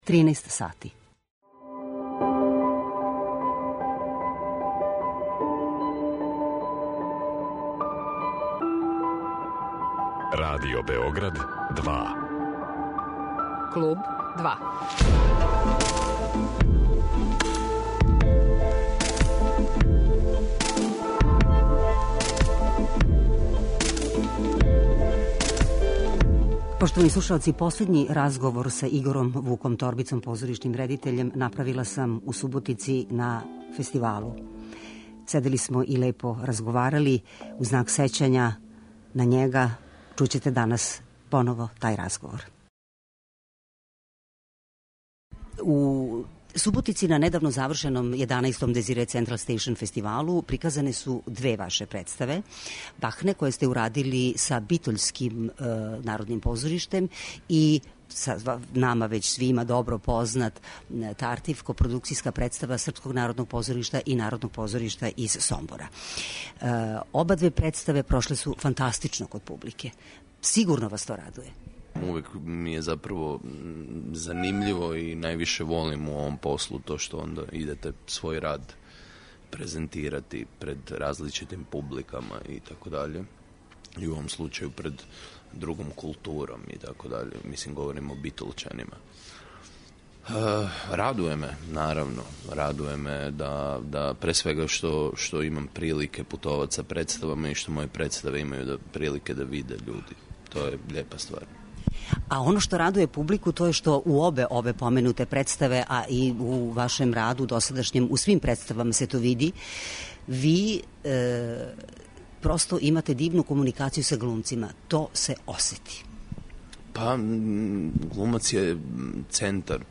разговор који је снимљен у Суботици 2020. на Desire central station фестивалу